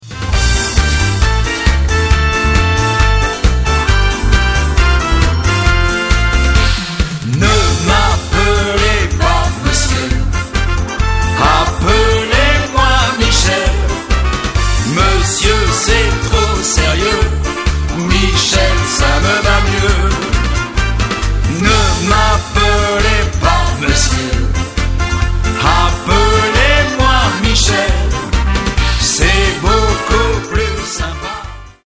extrait de l'album